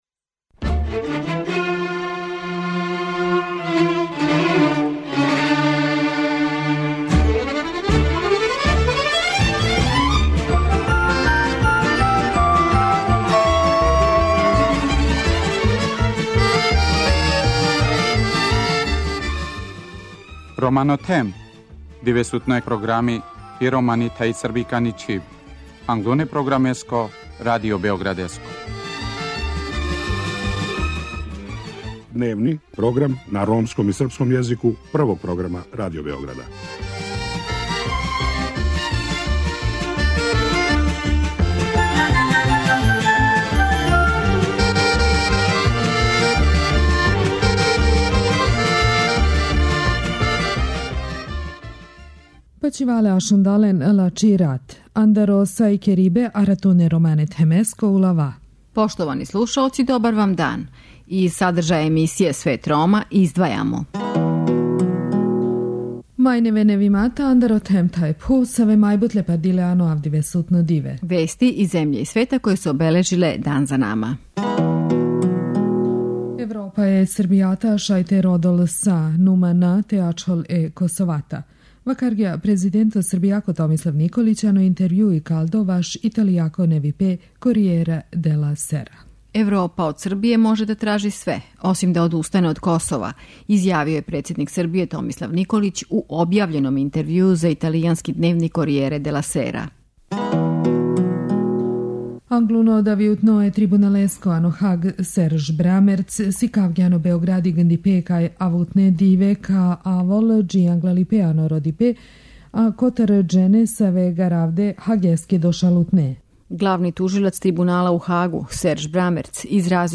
Гост вечерашње емисије је председник Националног савета Рома Витомир Михајловић, с којим разговарамо о резултатима спровођења Декаде укључења Рома.